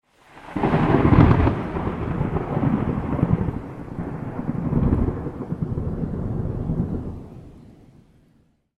thunder_26.ogg